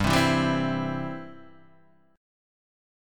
G6sus4 chord